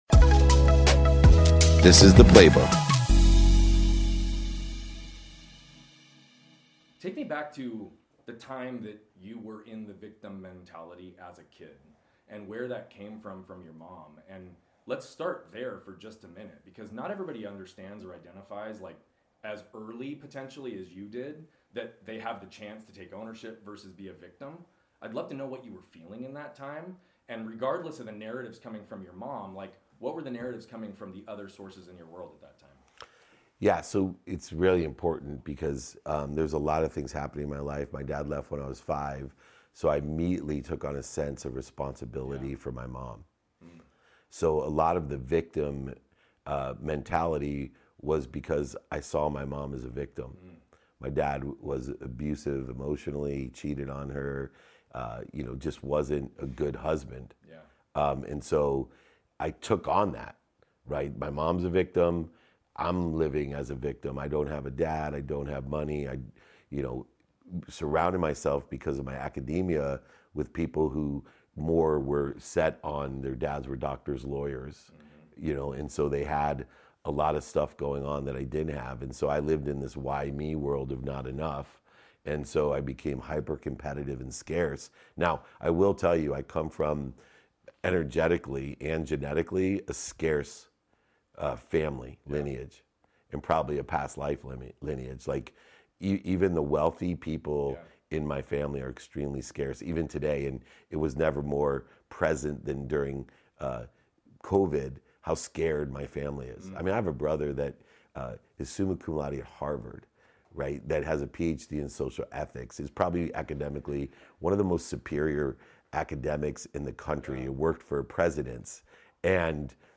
On today’s episode I participated in an honest and emotional conversation